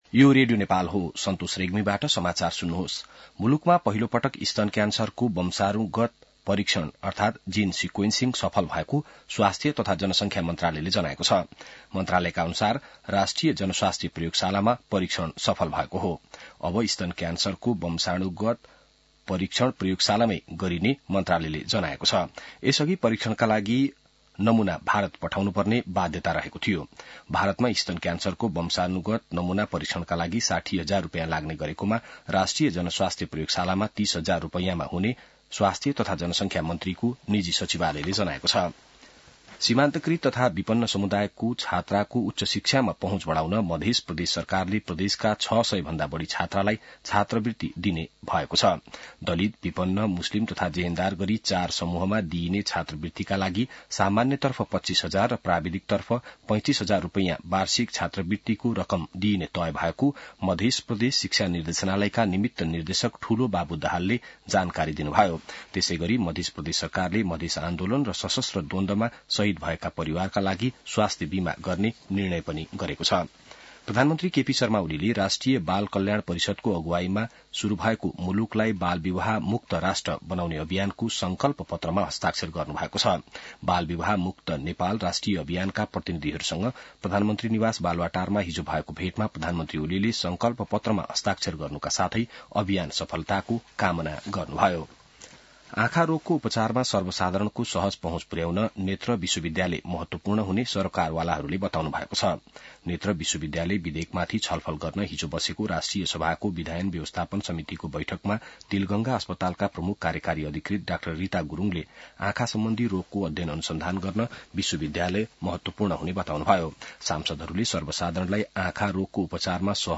बिहान ६ बजेको नेपाली समाचार : १८ पुष , २०८१